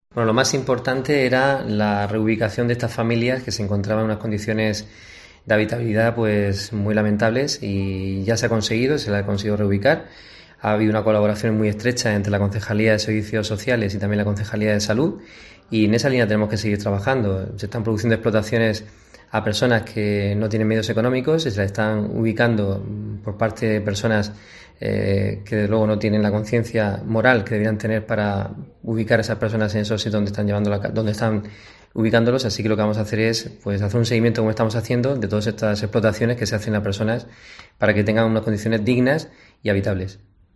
José Ángel Alfonso, alcalde de Molina de Segura